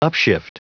Prononciation du mot upshift en anglais (fichier audio)
Prononciation du mot : upshift